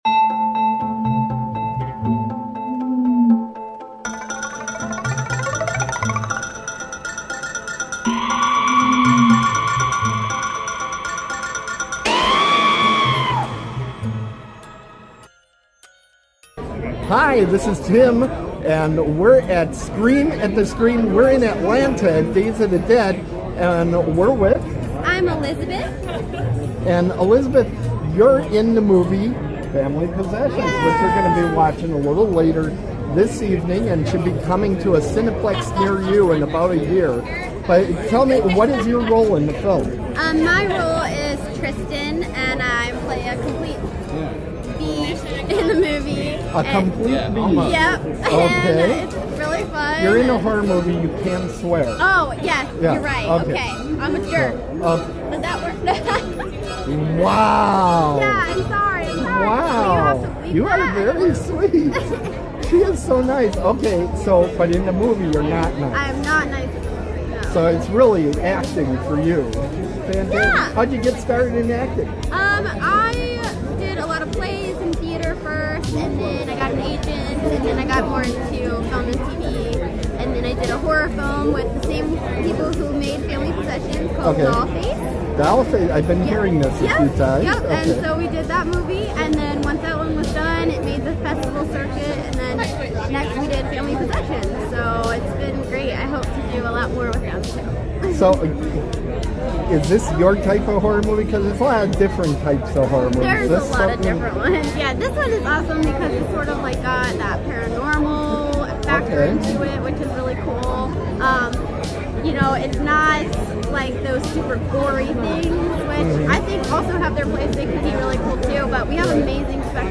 This interview was taped at Days of the Dead Atlanta on February 4, 2017.